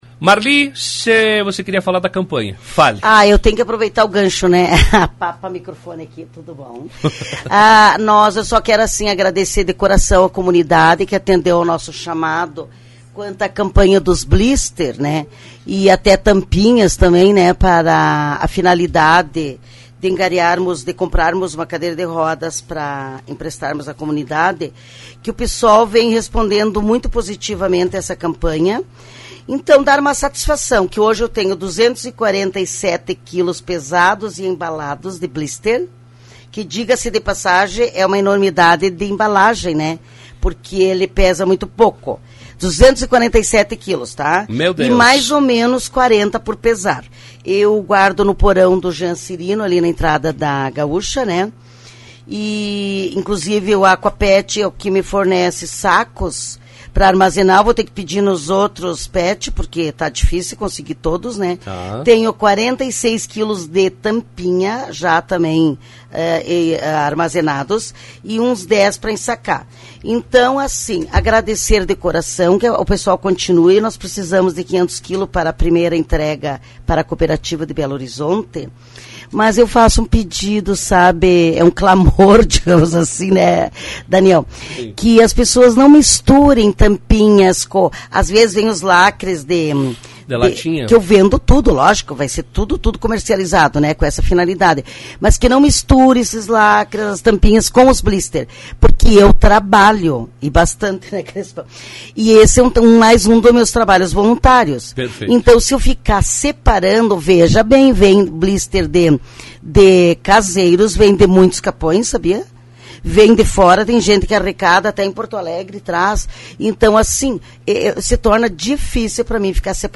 em entrevista para a Rádio Lagoa FM na manhã desta terça-feira